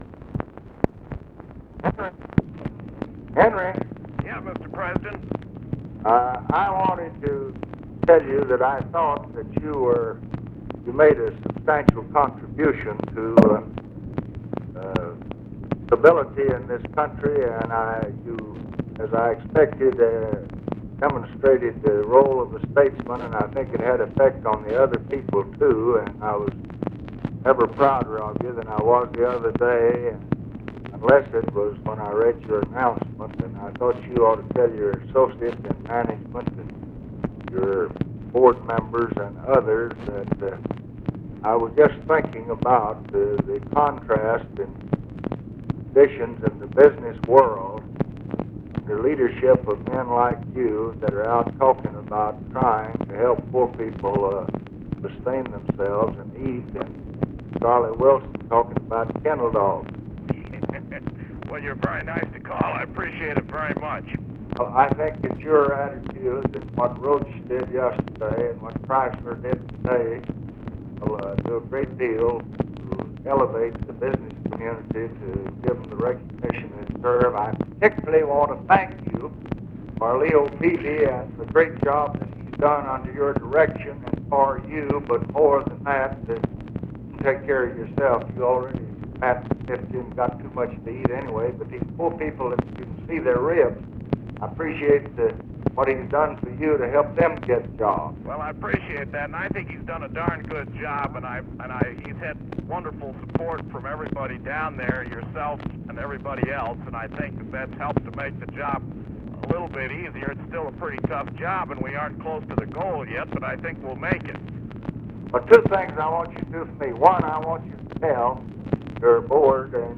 Conversation with HENRY FORD, September 26, 1968
Secret White House Tapes